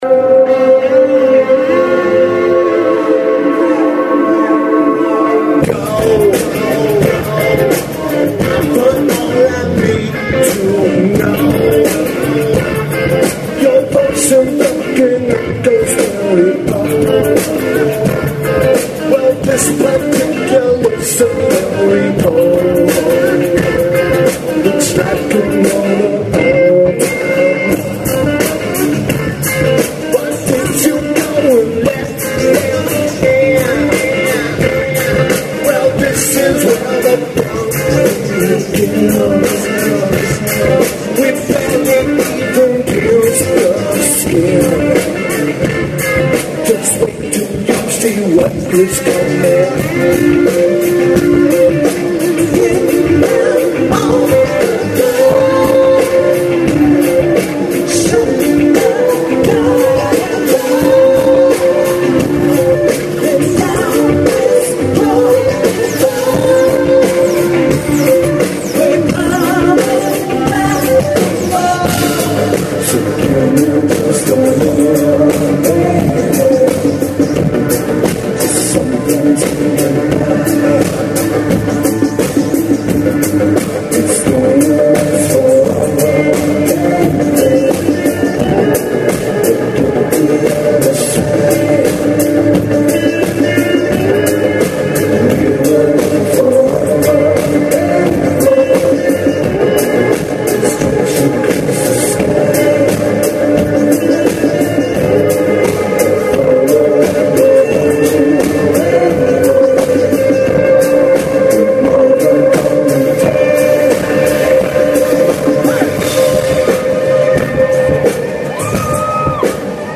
Sprint Center
Drums
Backing Vocals
Bass
Vocals/Guitar/Keyboards
Lineage: Audio - AUD (iPhone 4)